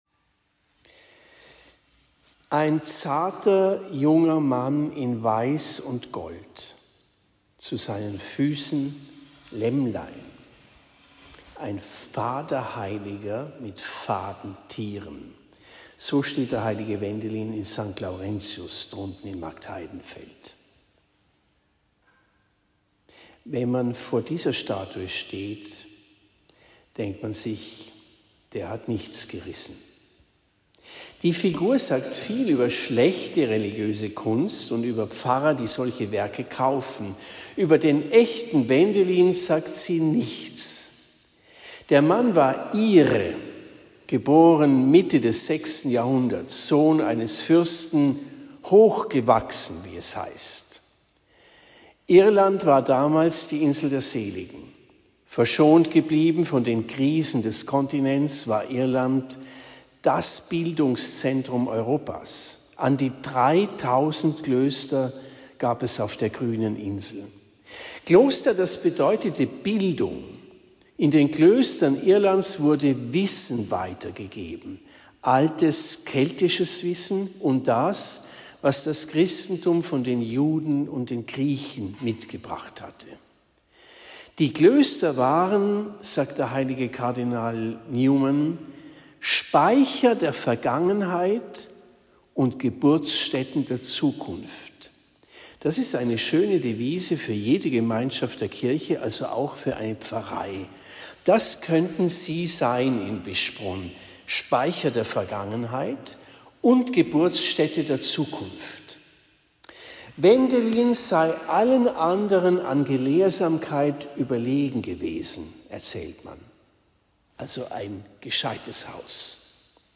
Predigt am 21. Oktober 2024 in Bischbrunn